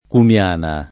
Cumiana [ kum L# na ]